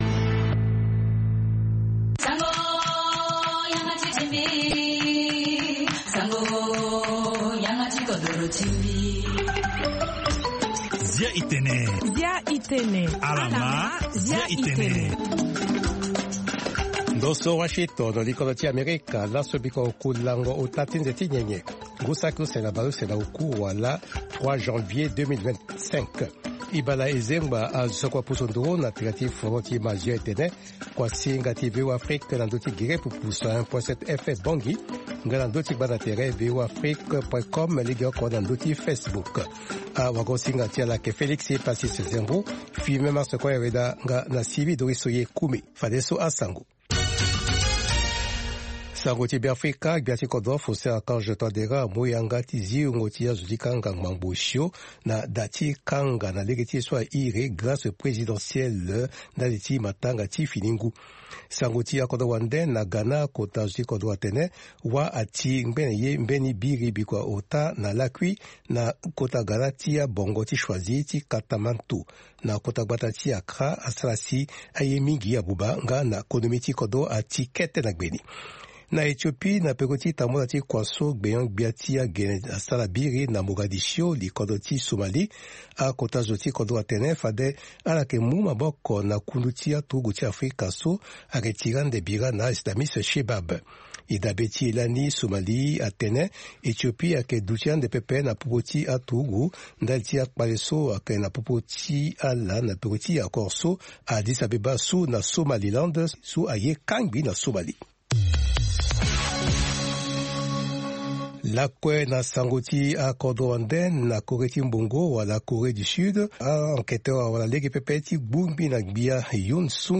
Zia i Tene, est un programme en sango comportant plusieurs rubriques sur l'actualité nationale et internationale, des interviews portant sur une analyse et des réactions sur des sujets divers ainsi qu'un volet consacré aux artistes.